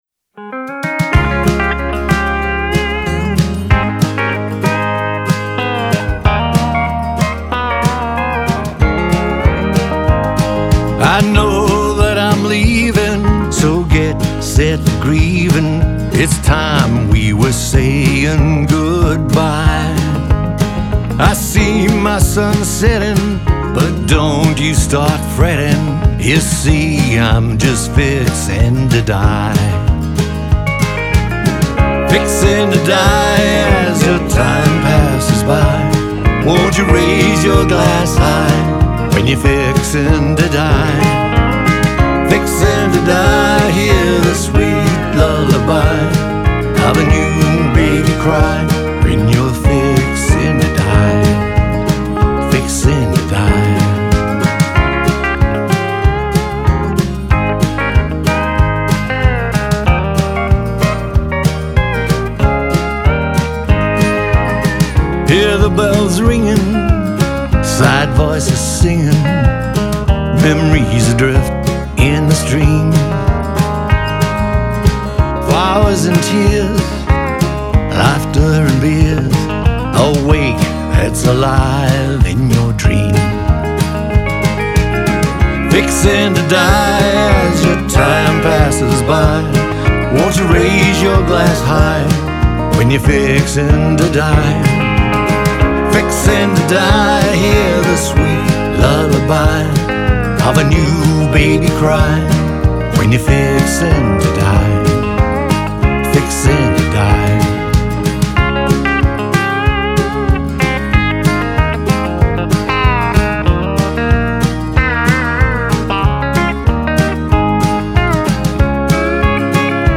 singer-songwriter
this intriguing, catchy number “sparkles”
guitar
pedal steel
backing vocals